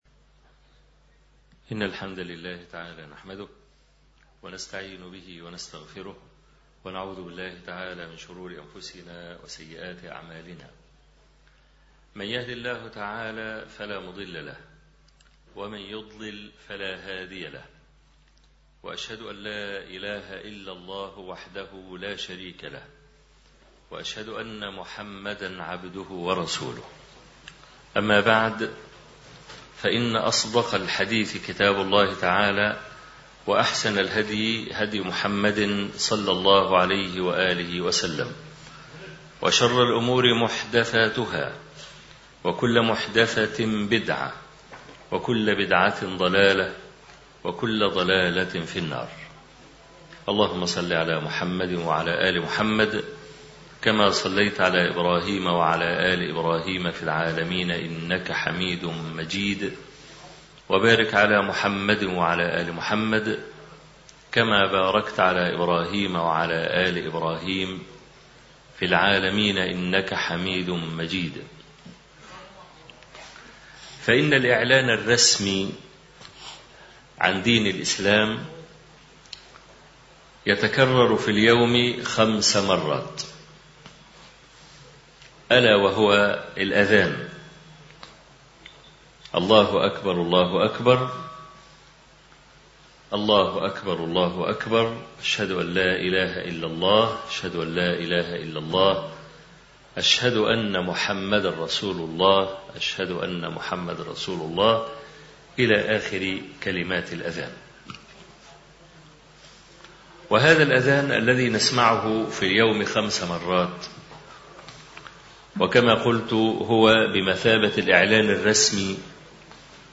محاضرة مدينة دورتموند بألمانيا